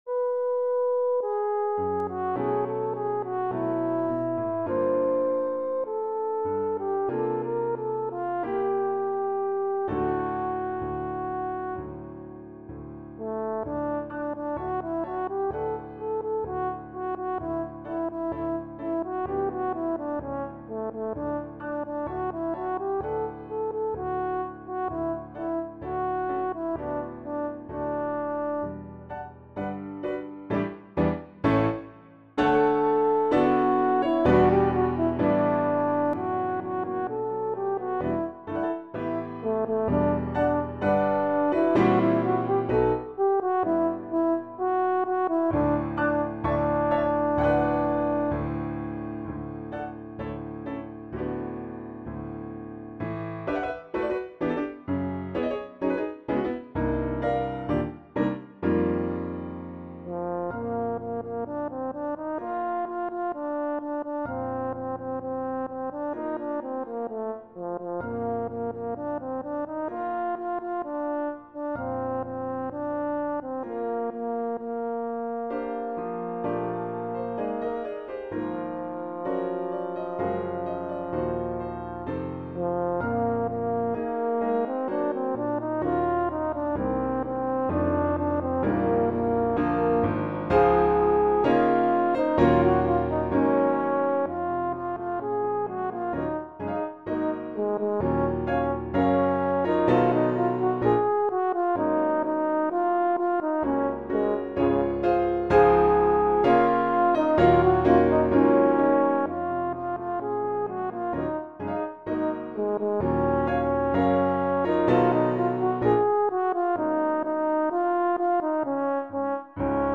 arranged for Horn in F and Piano